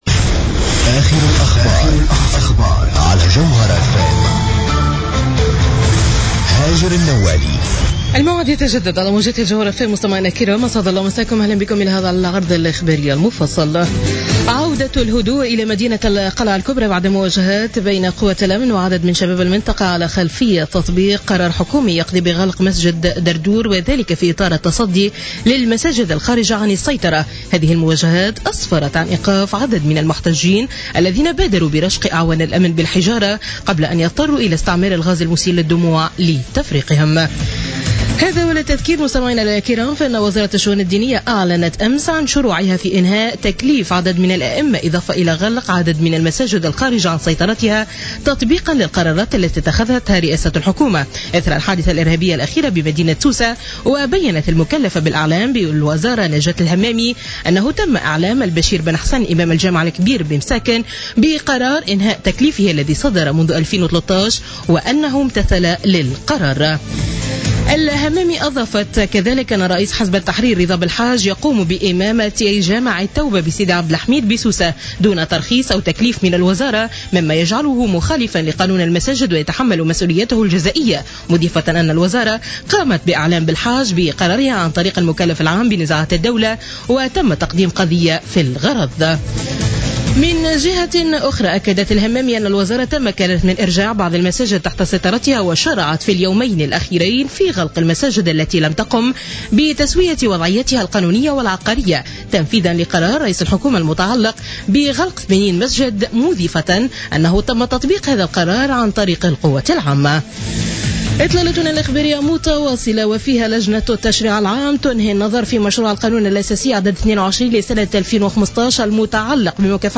نشرة أخبار منتصف الليل ليوم الجمعة 3 جويلية 2015